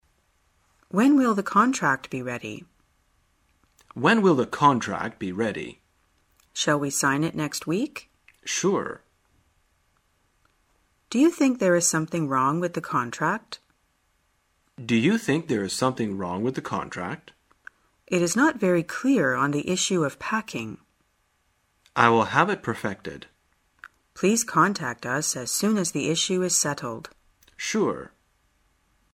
在线英语听力室生活口语天天说 第57期:怎样谈论合同的听力文件下载,《生活口语天天说》栏目将日常生活中最常用到的口语句型进行收集和重点讲解。真人发音配字幕帮助英语爱好者们练习听力并进行口语跟读。